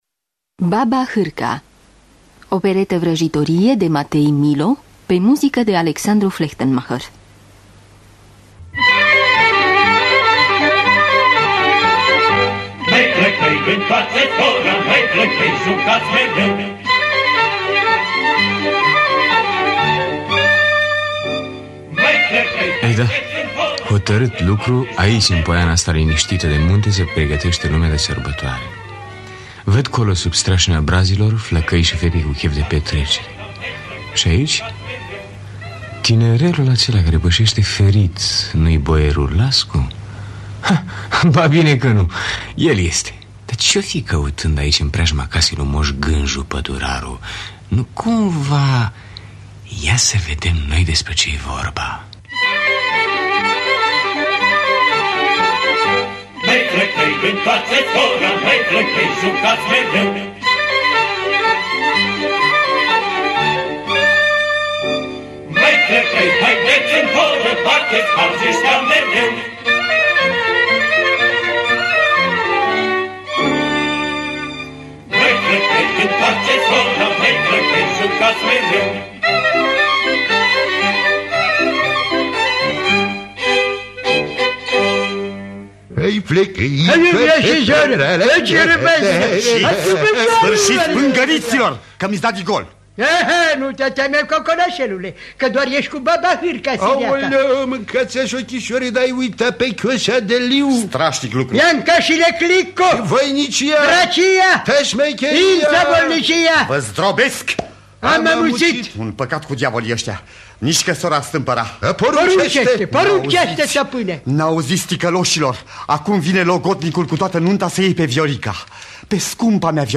Dramatizarea şi adaptarea radiofonică
Baba Harca, opereta vrajitorie de Matei Millo.mp3